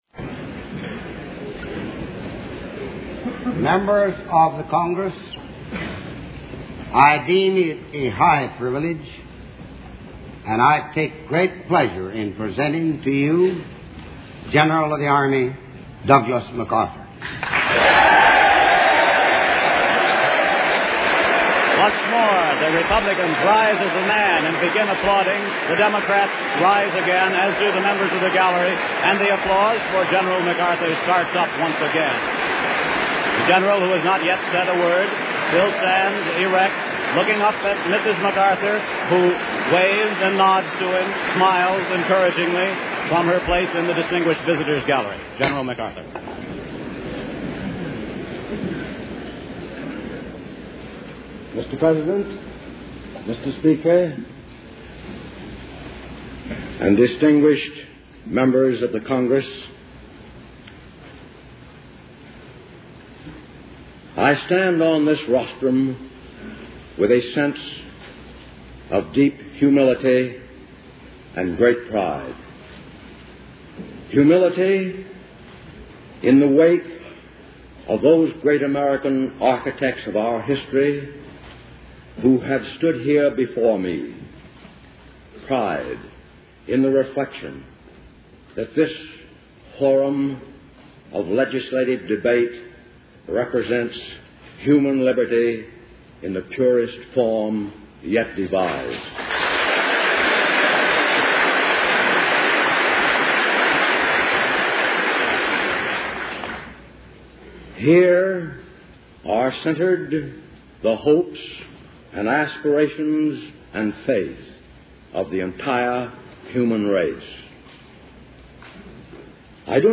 General Douglas MacArthur: Farewell Address to Congress